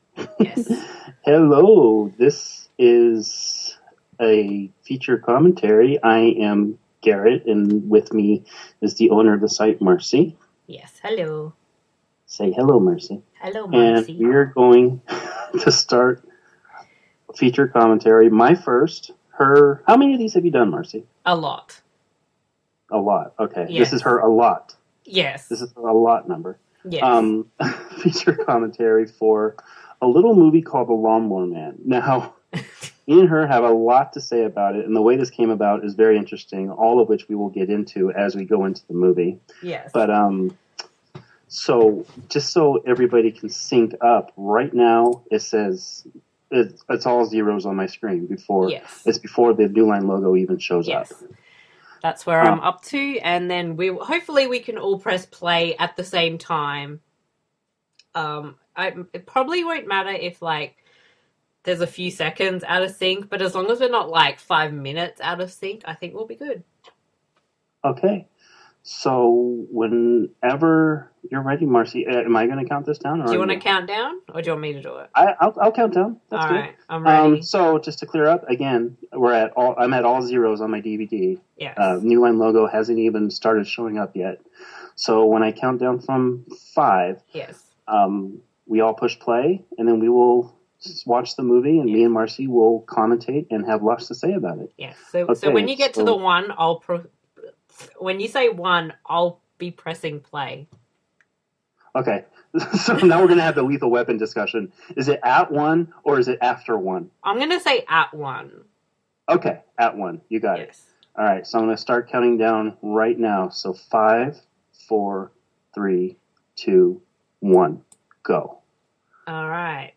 Super Podcast Audio Commentary: The Lawnmower Man (1992)
Disclaimer: This audio commentary isn’t meant to be taken 100 % seriously, it is an interesting and humorous look at a classic 90′s film.